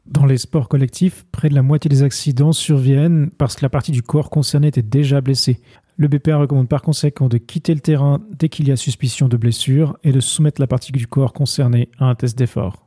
Désormais, vous pouvez télécharger librement des enregistrements audio de quelques-unes des déclarations figurant dans les communiqués.